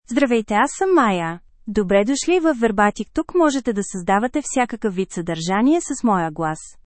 Maya — Female Bulgarian (Bulgaria) AI Voice | TTS, Voice Cloning & Video | Verbatik AI
FemaleBulgarian (Bulgaria)
Maya is a female AI voice for Bulgarian (Bulgaria).
Voice sample
Listen to Maya's female Bulgarian voice.